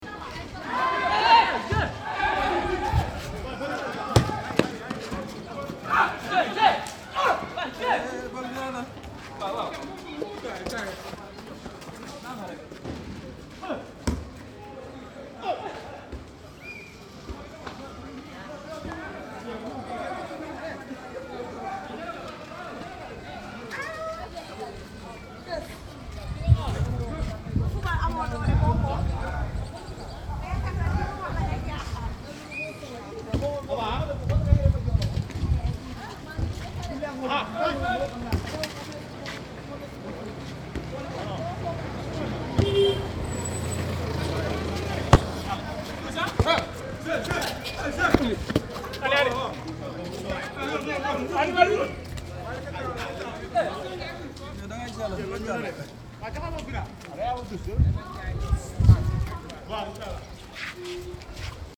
Dans la rue, un match de foot s'improvise.
17_match_foot.mp3